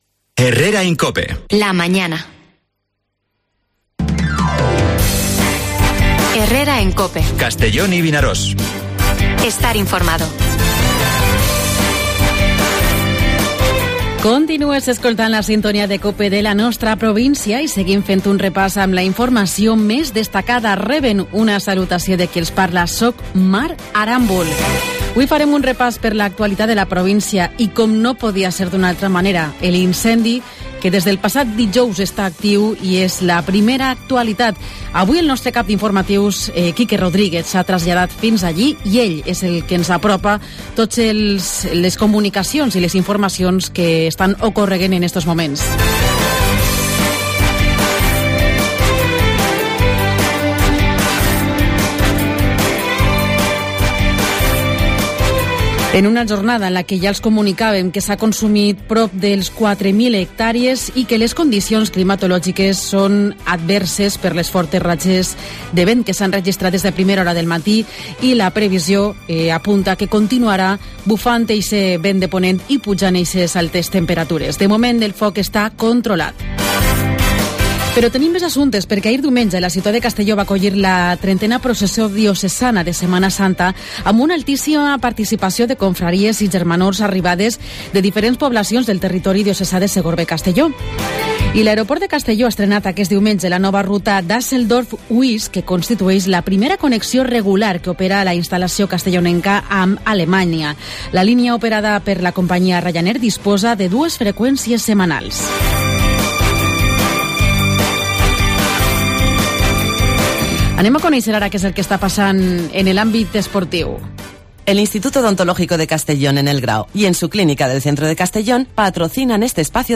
AUDIO: Programas especiales con conexiones desde el Puesto de Mando Avanzado para conocer la actualidad del incendio